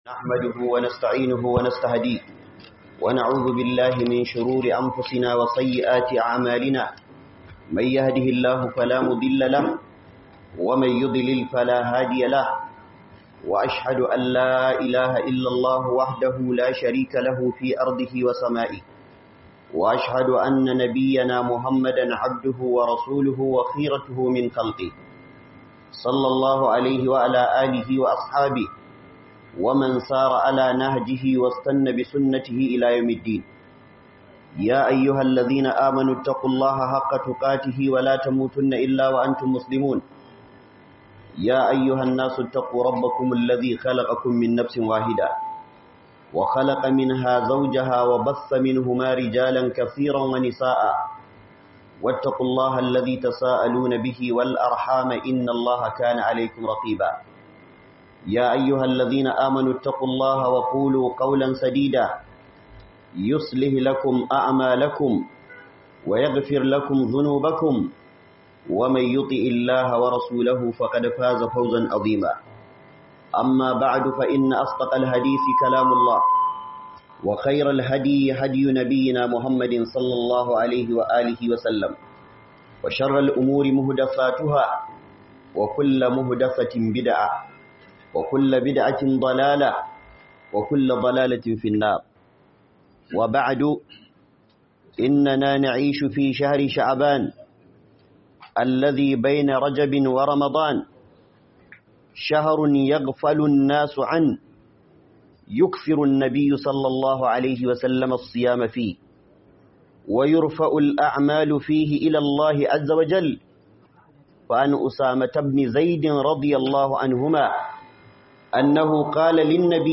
SULHUNTA JUNA A WATAN SHAABAN - Huduba